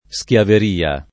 schiaveria [ S k L aver & a ] s. f.